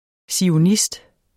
Udtale [ sioˈnisd ]